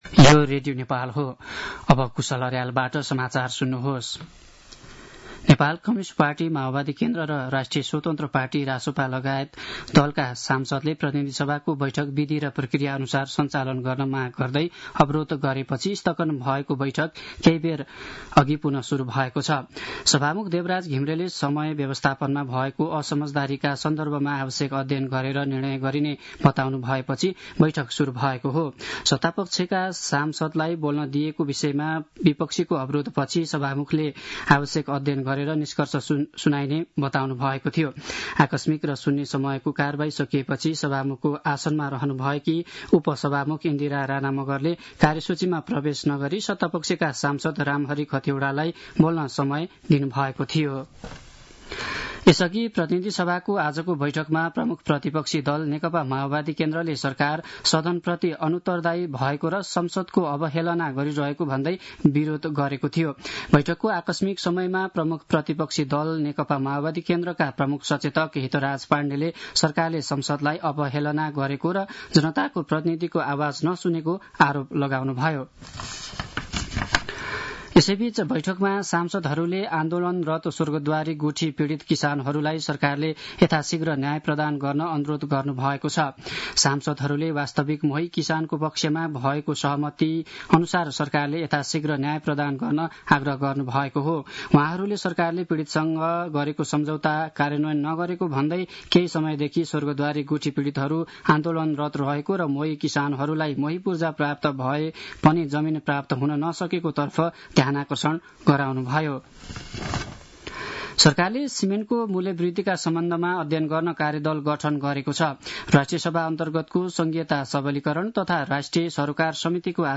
साँझ ५ बजेको नेपाली समाचार : ३० माघ , २०८१